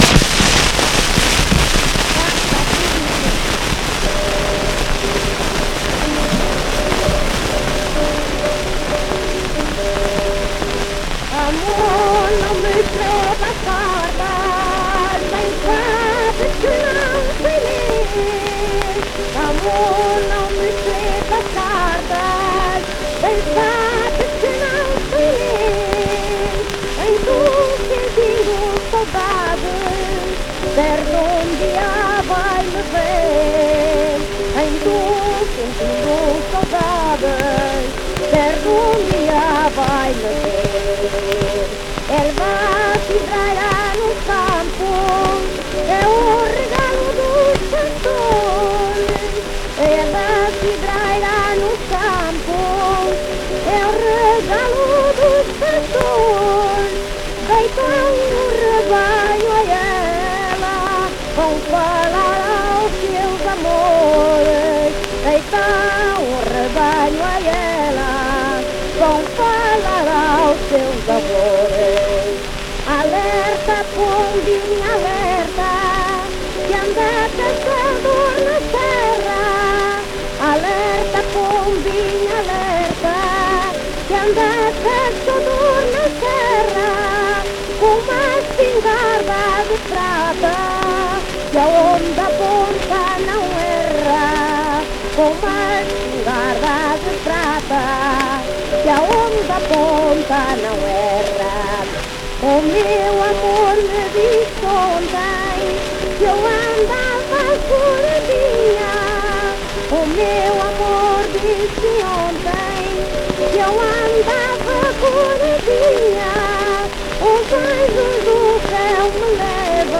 inetmd-fcsh-ifpxx-mntd-audio-fado_beka-639.mp3